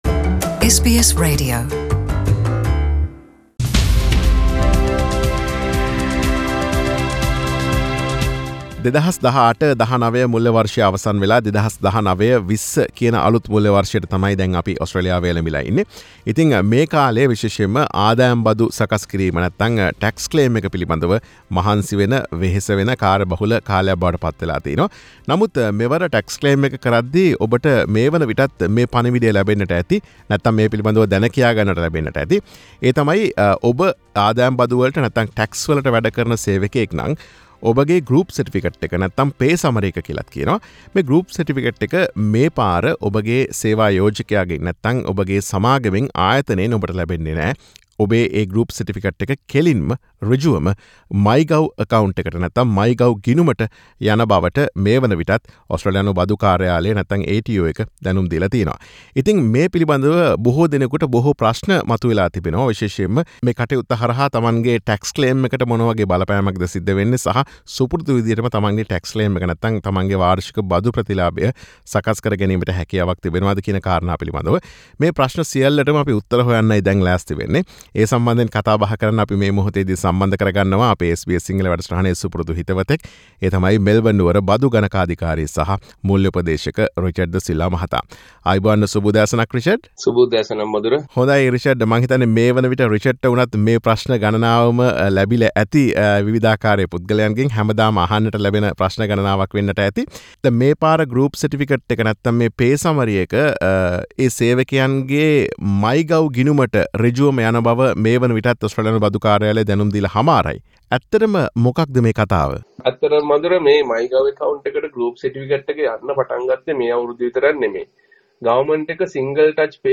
SBS සිංහල වැඩසටහන සිදු කළ සාකච්ඡාව.